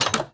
Minecraft Version Minecraft Version latest Latest Release | Latest Snapshot latest / assets / minecraft / sounds / block / iron_door / open3.ogg Compare With Compare With Latest Release | Latest Snapshot